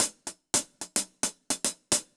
Index of /musicradar/ultimate-hihat-samples/110bpm
UHH_AcoustiHatB_110-04.wav